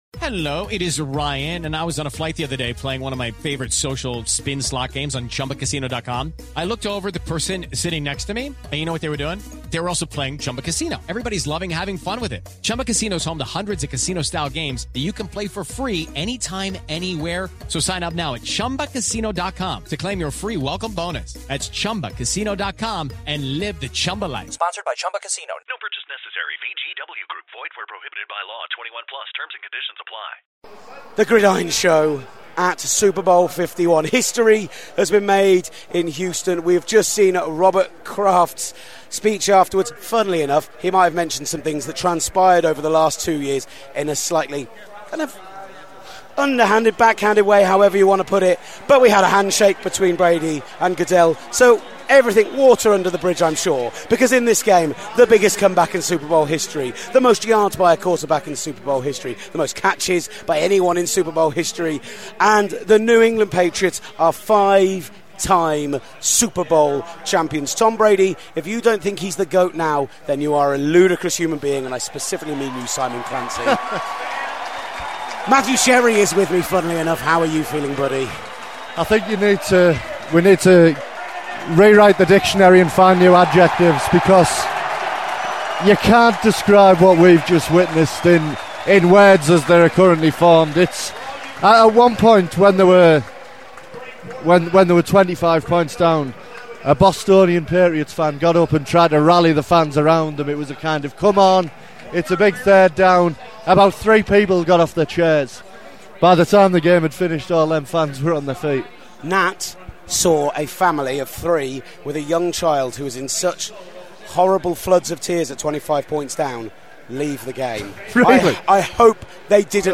We'll hear from the locker rooms including Patriots Logan Ryan, Chris Long & Marcus Cannon plus Falcons safety Ricardo Allen. And we look at the post SB coaching moves for ATL and out on the west coast.